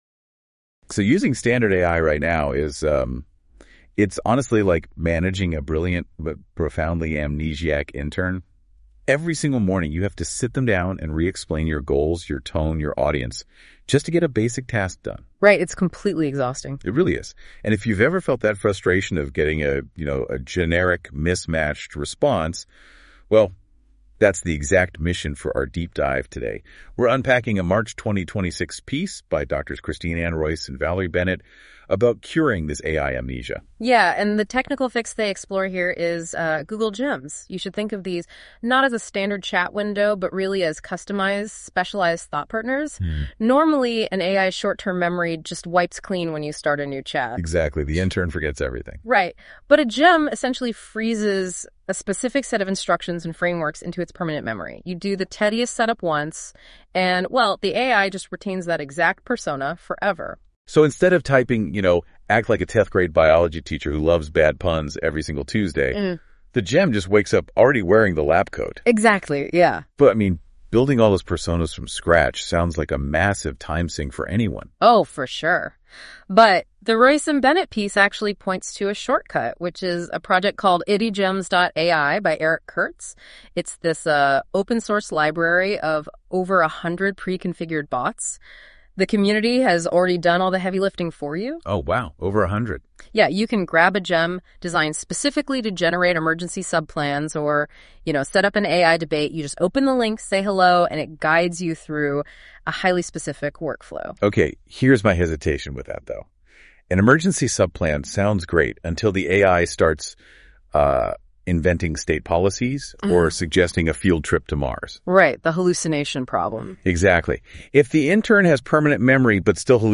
The following video and audio synopsis of this blog were generated using Google NotebookLM's features.